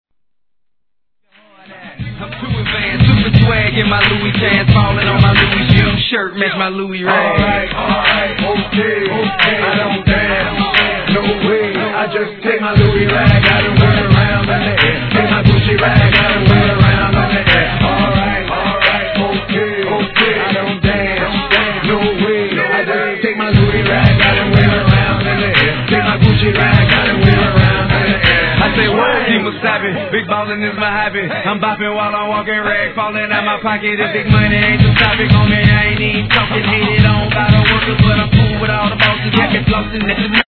HIP HOP/R&B
BPM 89